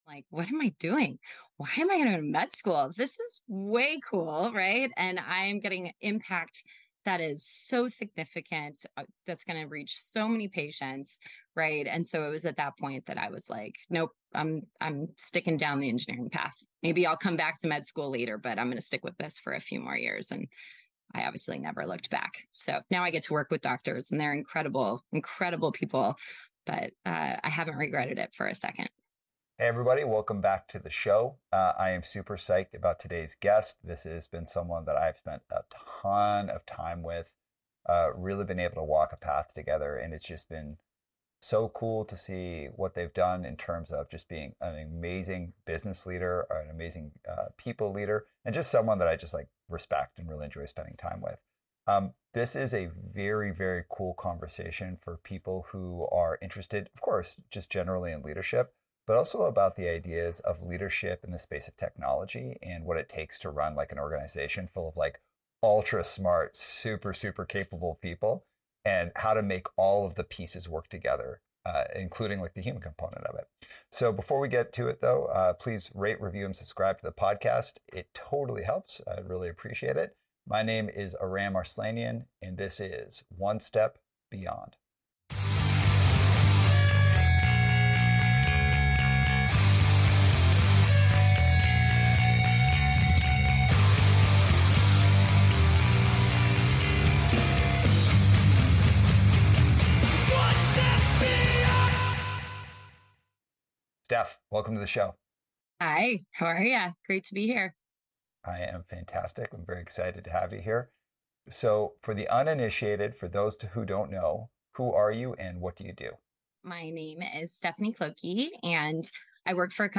This episode dives deep into working in the innovation space. This conversation explores ideation and making things happen.